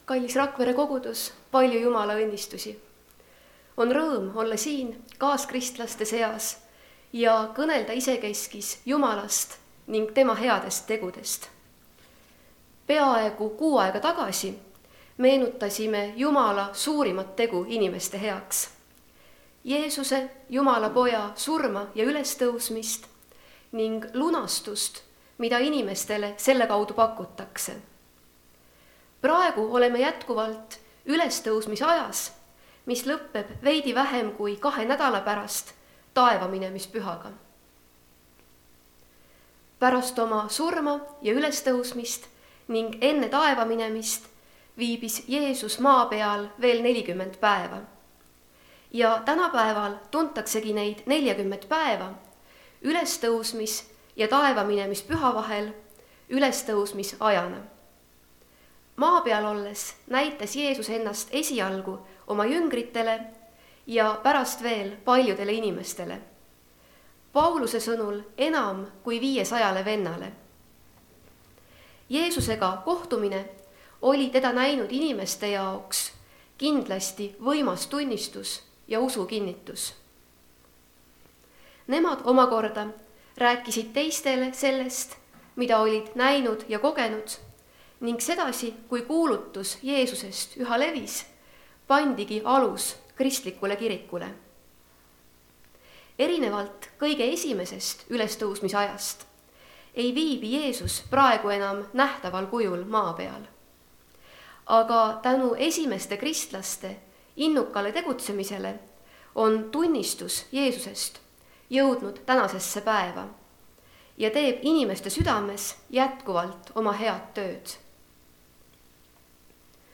kategooria Audio / Jutlused / Teised